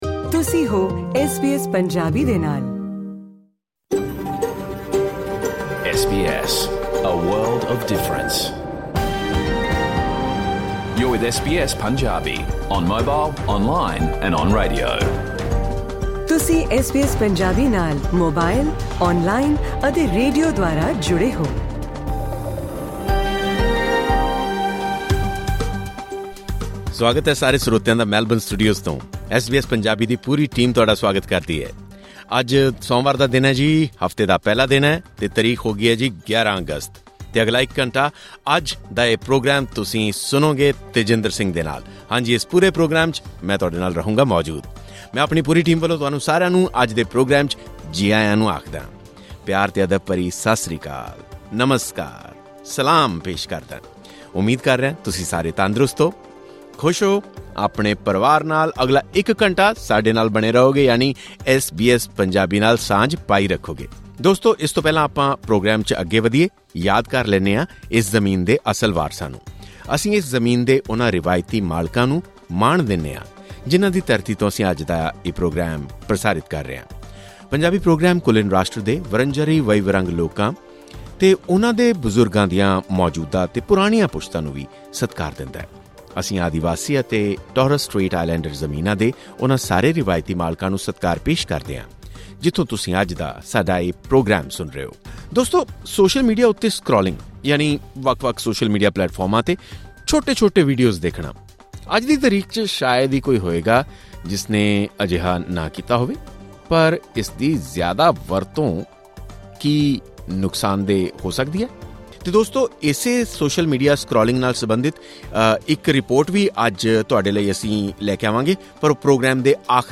Listen to the full program of SBS Punjabi. It includes news and current affairs along with news from Punjab. Interaction with a migration agent regarding the recent changes to